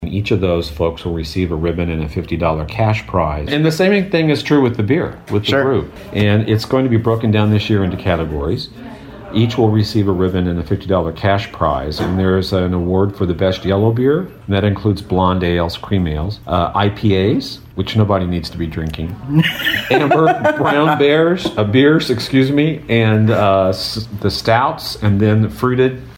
Fred Vallowe, McLeansboro City Clerk, was a guest on WROY’s Open Line program recently and explained how the event came about…